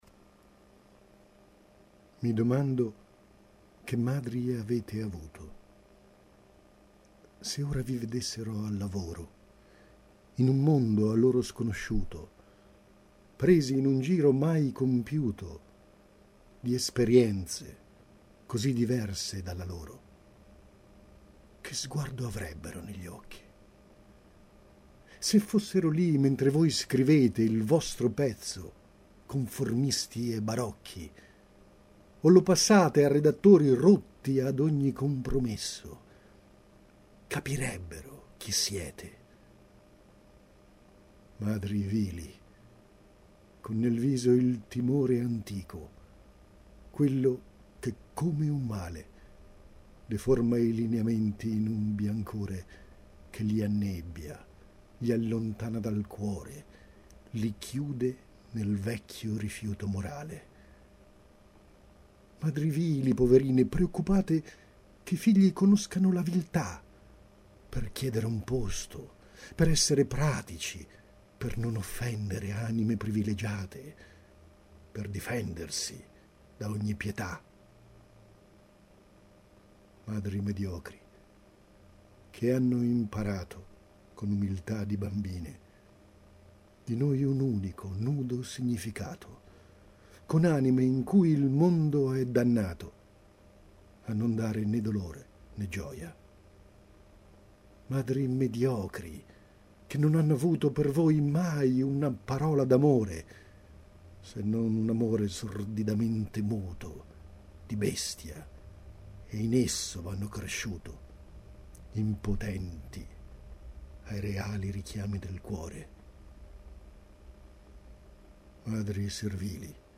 Recitazione